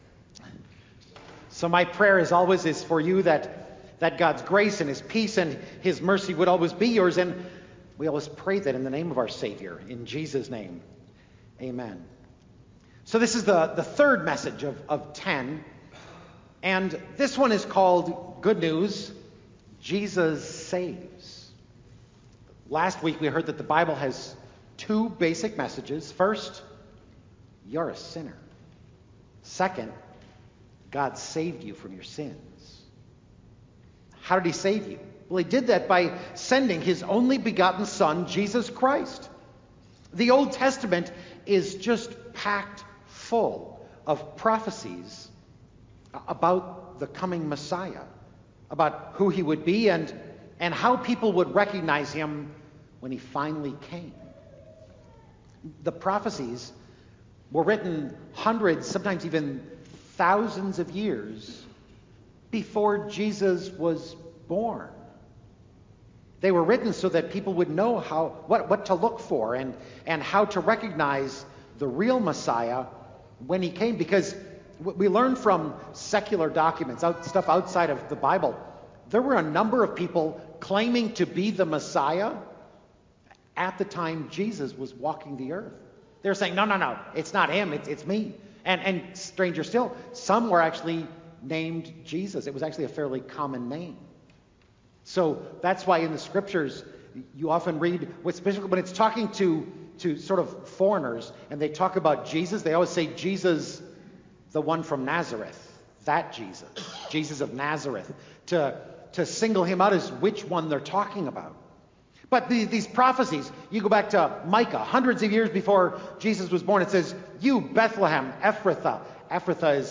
Sermon Audio
07-08-Good-News-Jesus-Saves-Sermon-Audio-CD.mp3